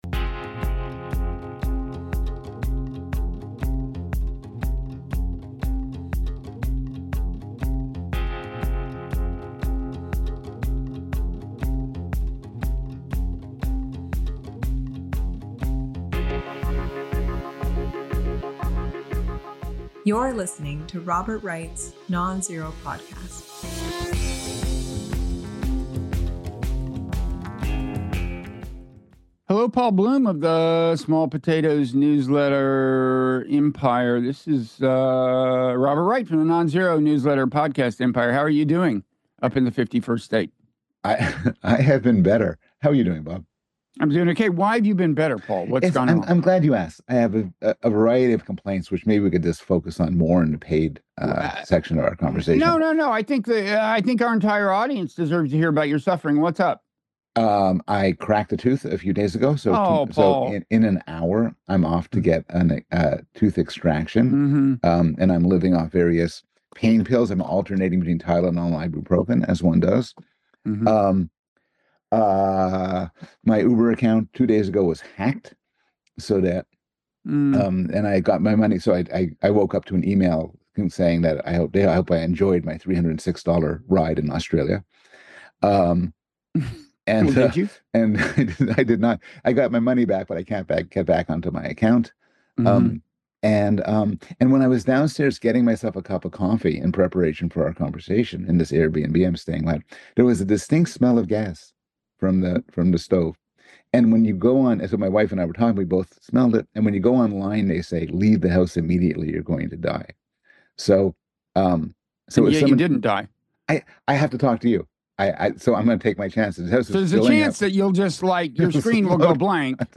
Worthwhile Canadian Humiliation (Robert Wright & Paul Bloom) (Robert Wright interviews Paul Bloom; 17 Jan 2025) | Padverb